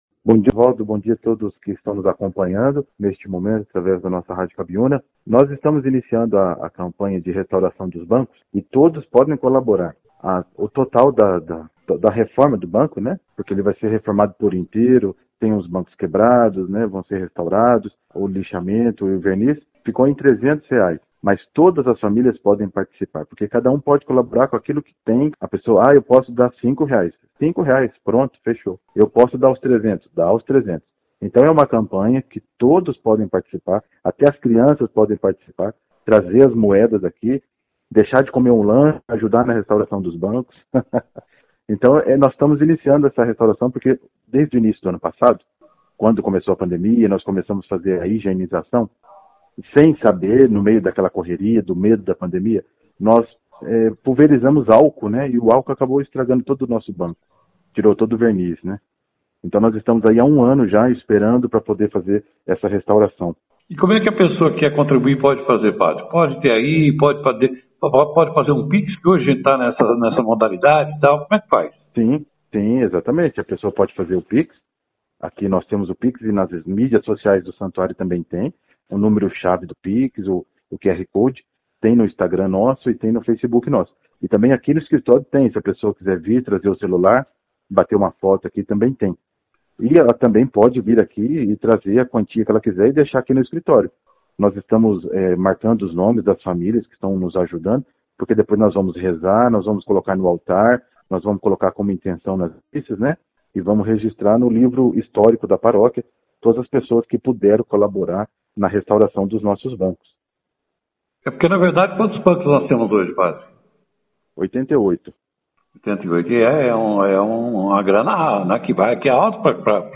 participou da 1ª edição do jornal Operação Cidade